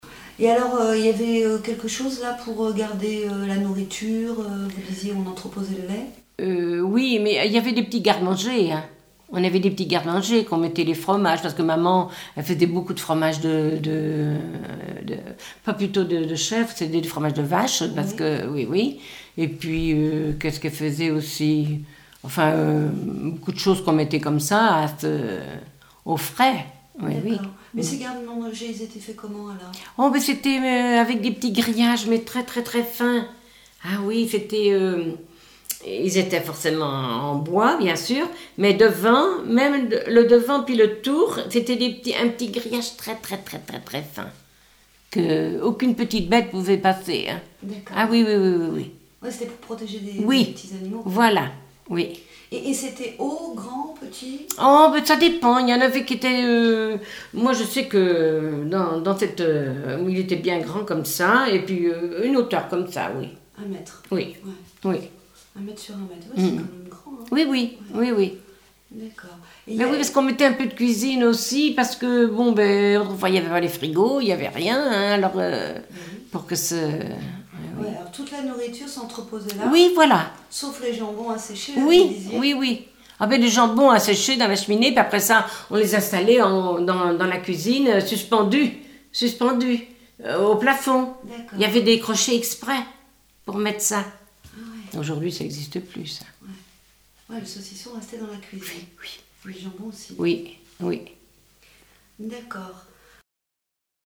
Témoignages sur la vie à la ferme